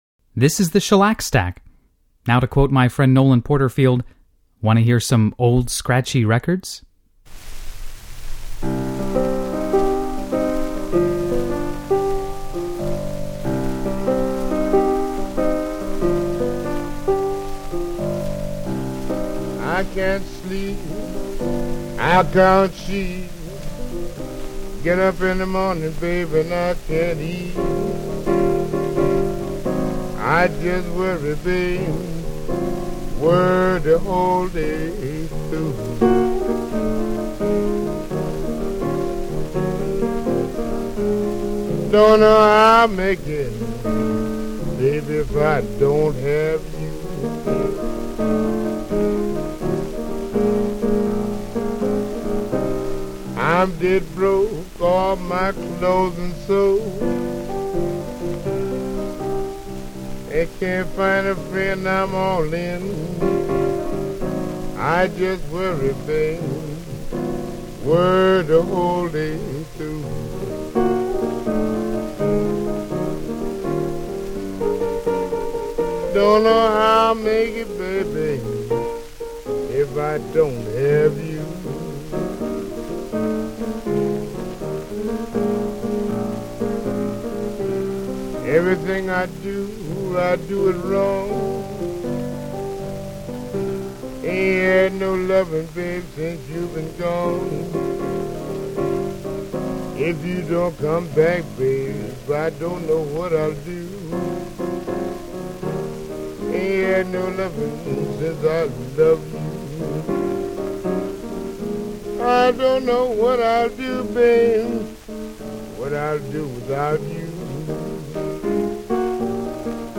boogie-woogie pianist
singers